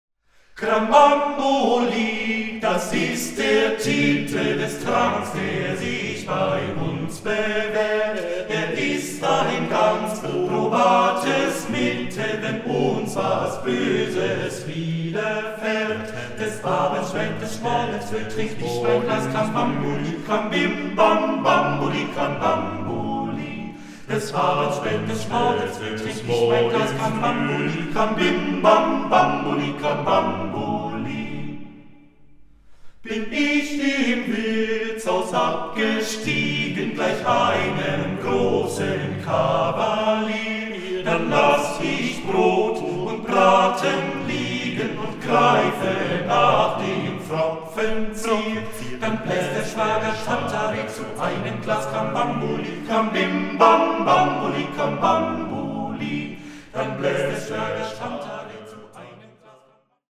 award-winning vocal ensemble
the eight singers revive an entire folk culture!